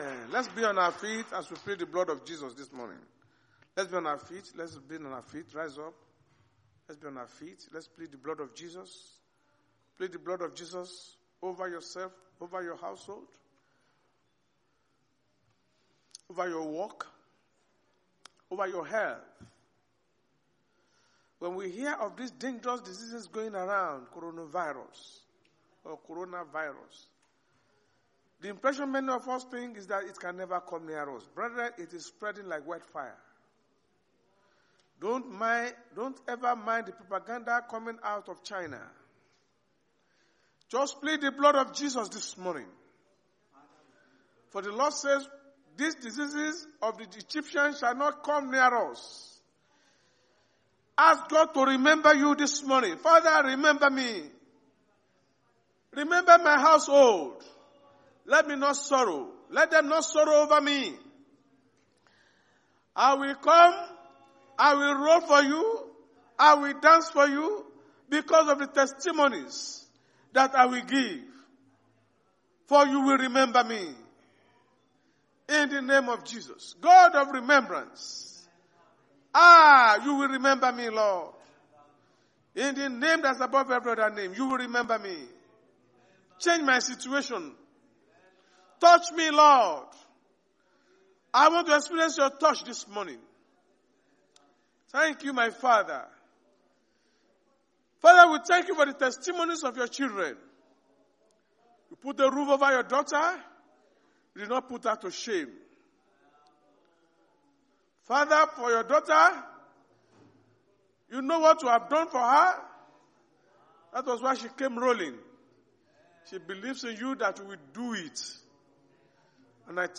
Sunday Sermon: When God Remembers You
Service Type: Sunday Church Service